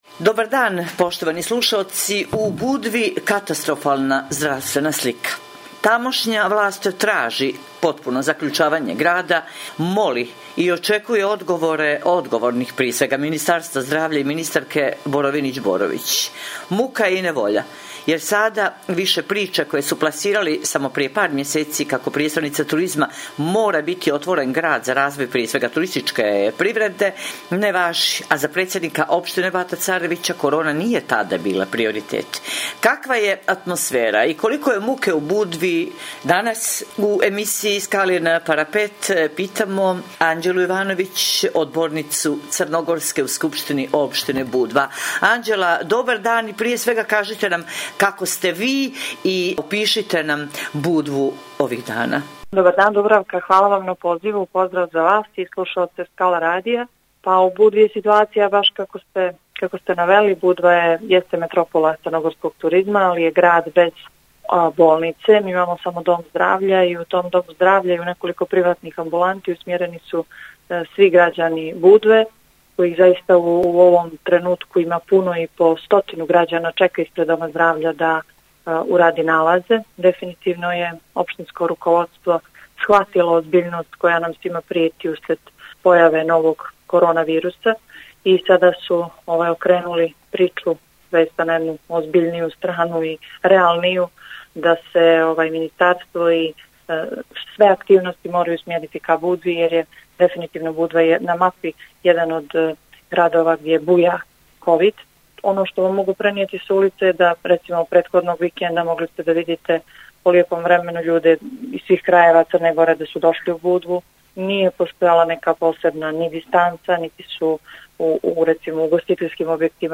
Poštujući pravila nadležnih zbog epidemije korona virusa Skala radio će emisiju “Skalin parapet” emitovati u izmijenjenoj formi i u skraćenom trajanju, budući da nema gostovanja u studiju Skala radija do daljnjeg.
Stoga ćemo razgovore obavljati posredstvom elektronske komunikacije i telefonom, kako bi javnost bila pravovremeno informisana o svemu što cijenimo aktuelnim, preventivnim i edukativnim u danima kada moramo biti doma.